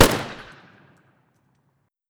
AR1_Shoot 01.wav